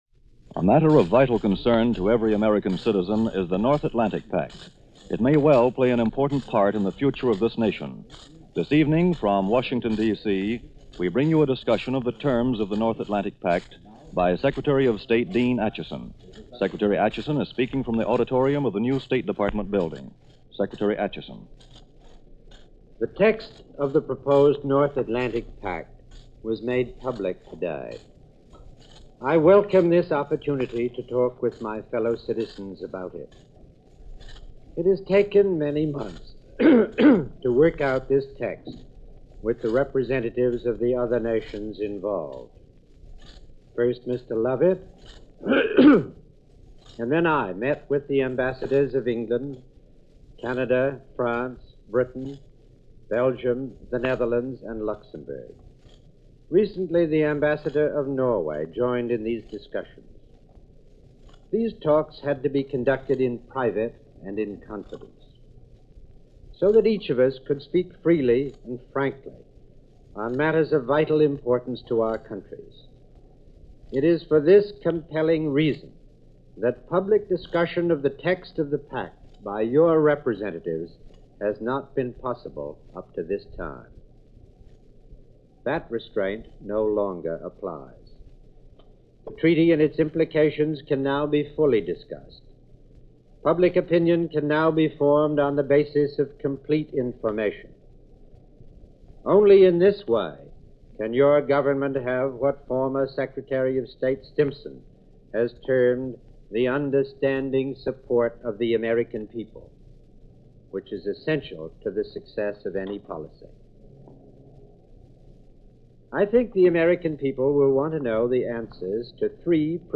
Dean Acheson – Address on NATO – March 18, 1949